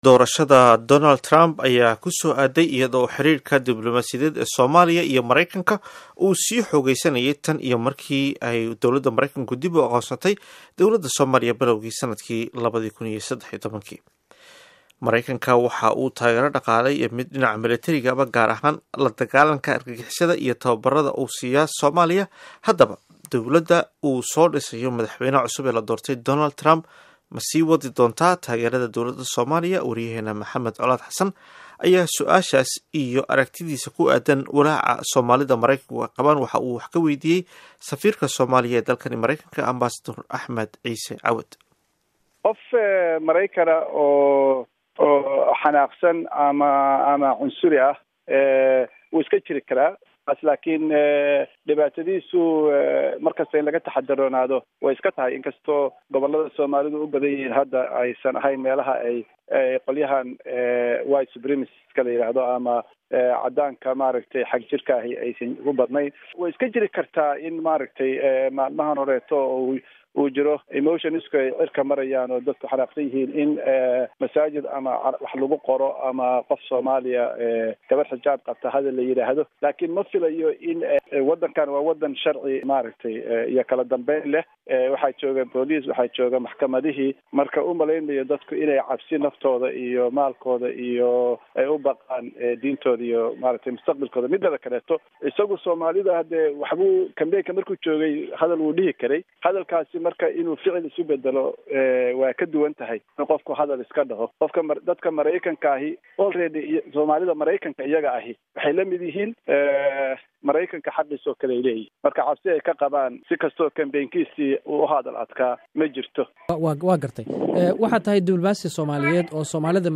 WAREYSIGA AMBASADOOR AXMED CAWAD